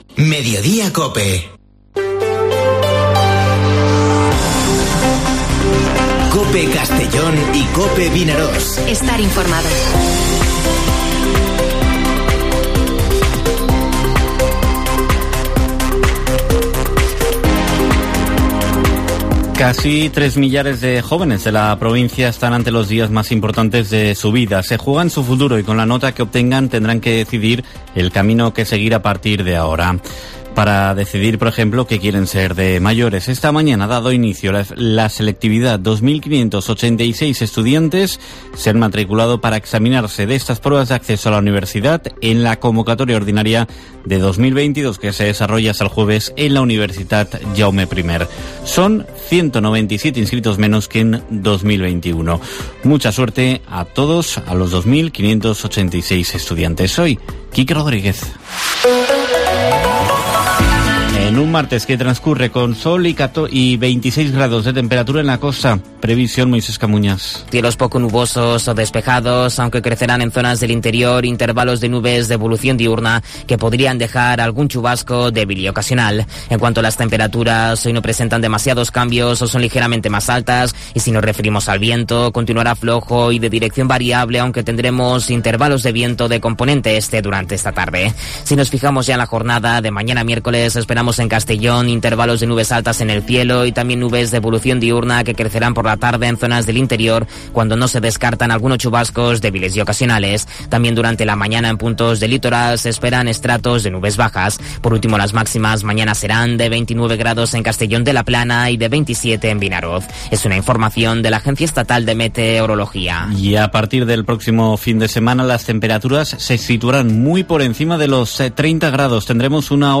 Informativo Mediodía COPE en la provincia de Castellón (07/06/2022)